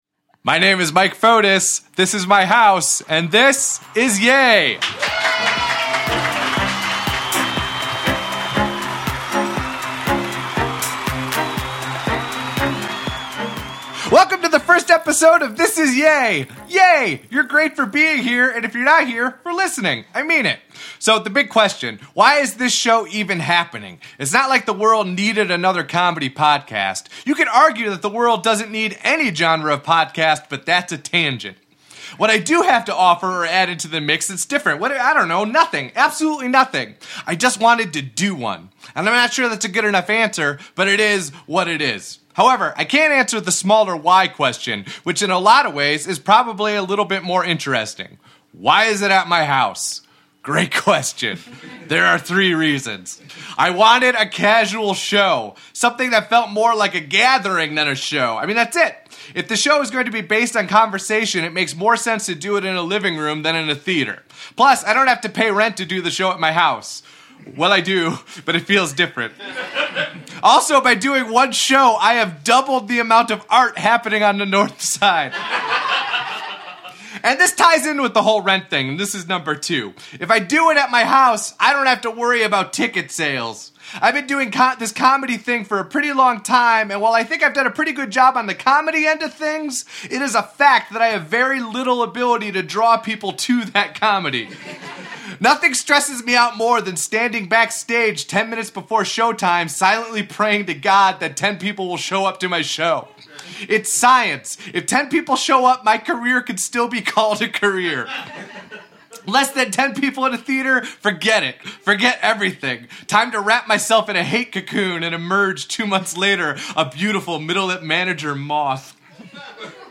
Later, they read a “RADIO” sketch about Jarzz legend, Miles Darvis. The show wraps up with an audience game that destroyed everyone’s sense of reality.